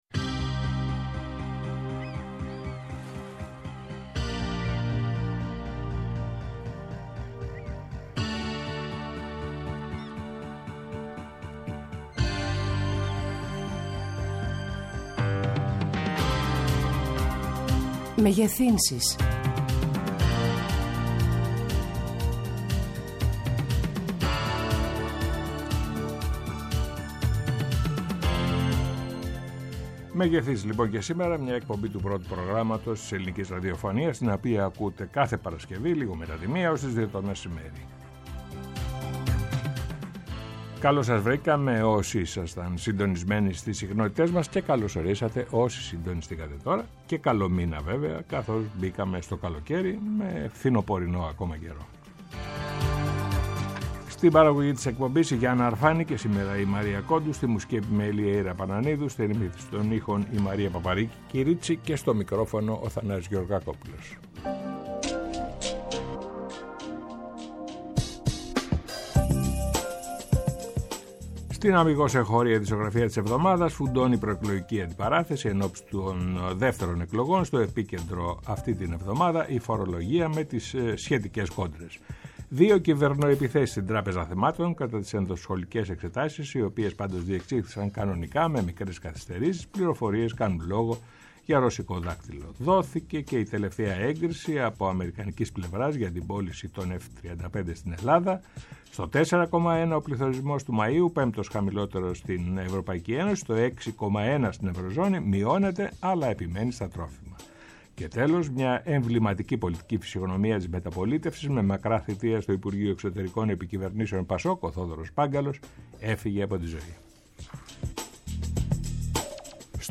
Καλεσμένοι τηλεφωνικά
Συνεντεύξεις